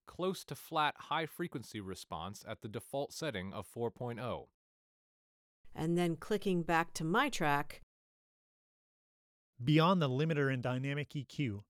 Example spectrogram of clean speech used as network training target.
clean_speech.wav